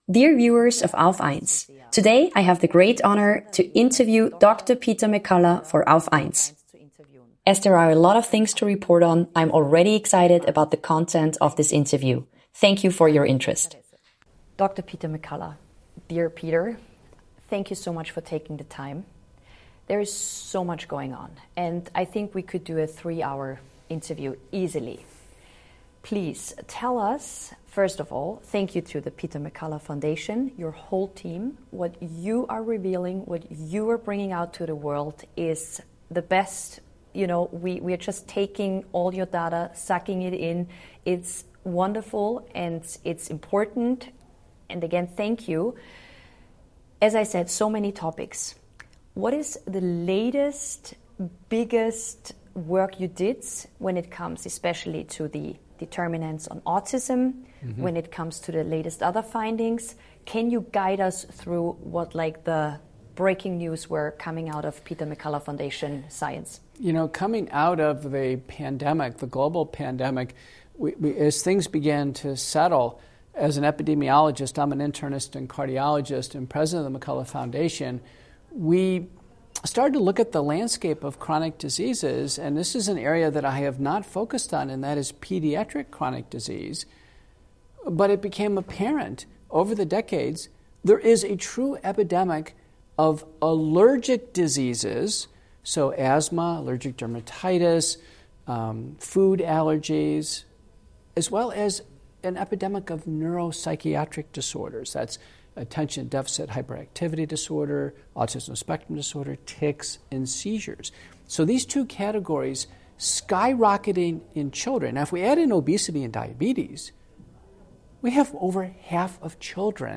in-depth conversation